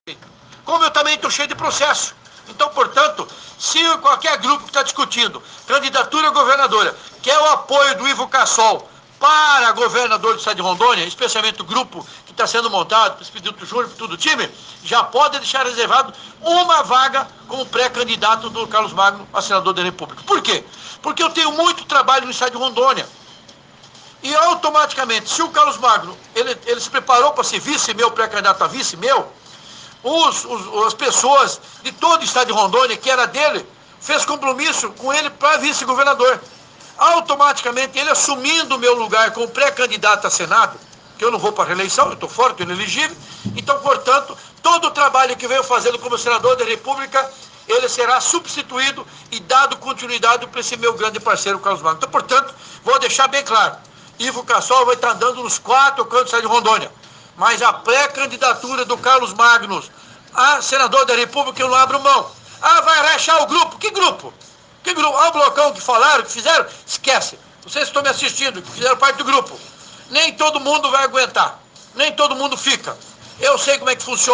Em entrevista recente a uma emissora de rádio na região da Zona da Mata, o senador Ivo Cassol (PP) ameaçou implodir o “grupão”, aliança que reúne 11 partidos para eleger o governador, a maior parte da bancada federal e emplacar vários deputados na Assembléia Legislativa de Rondônia este ano.